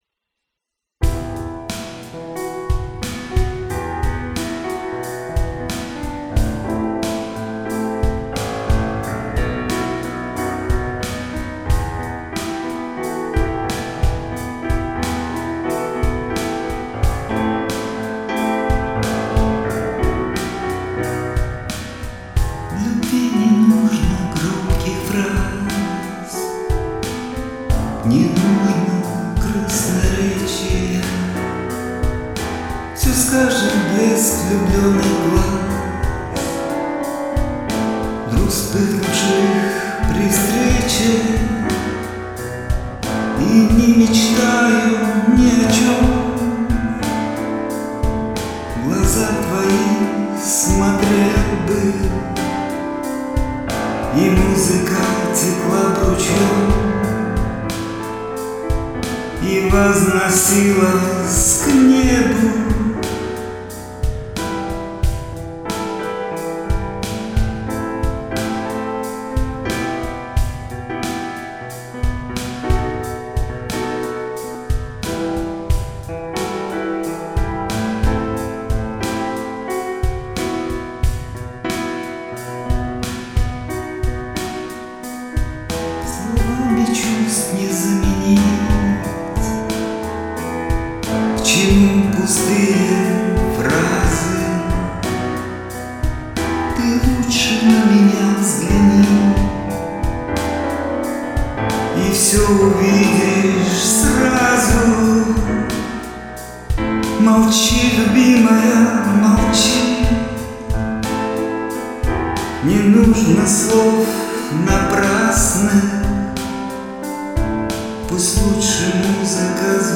пиано